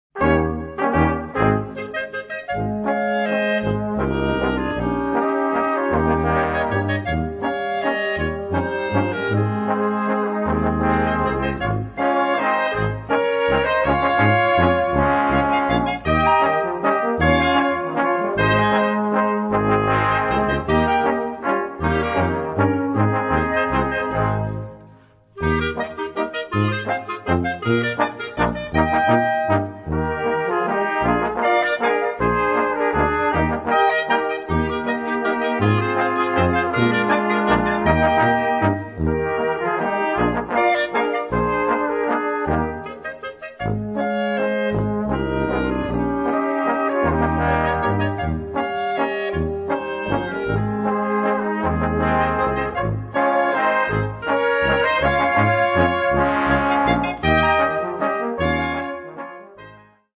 Gattung: für gemischtes Ensemble
Besetzung: Ensemble gemischt